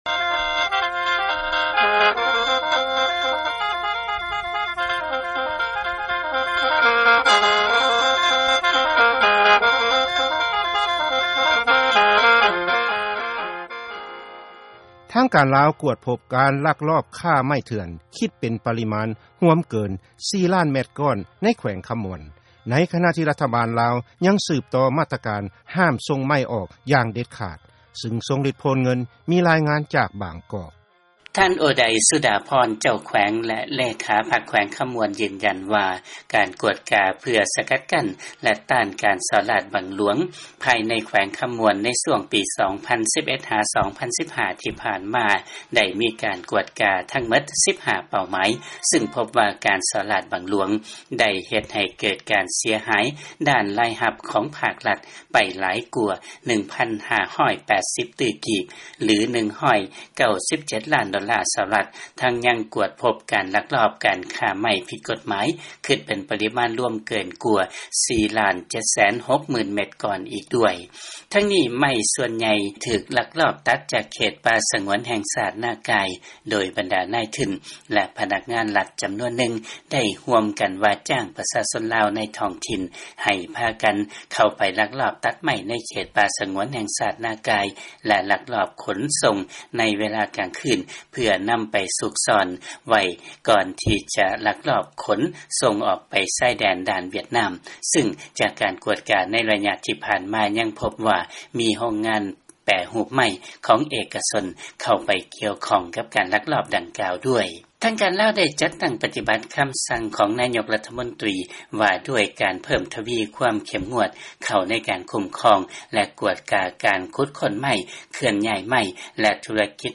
ເຊີນຟັງລາຍງານ ມີການລັກລອບ ຄ້າໄມ້ເຖື່ອນ ລວມເກີນກວ່າ 4 ລ້ານແມັດກ້ອນ ໃນແຂວງຄຳມ່ວນ